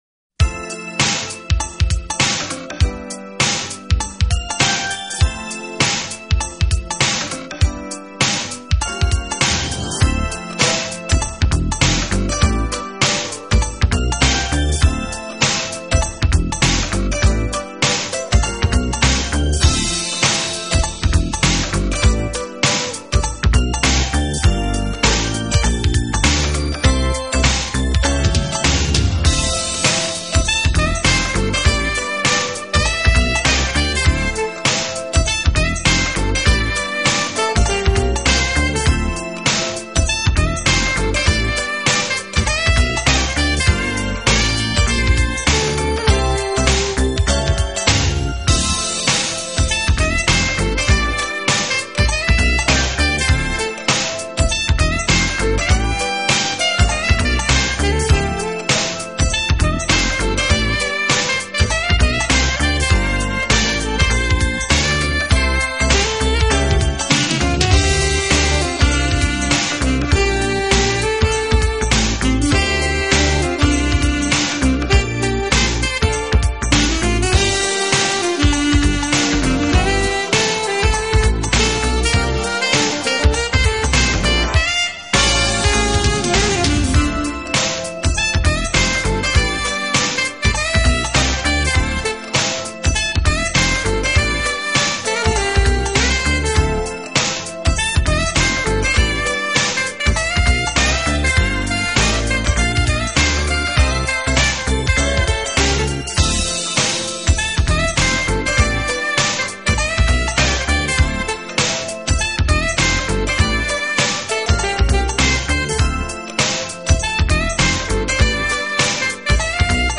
Genre: Smooth Jazz
sax is what's on display here.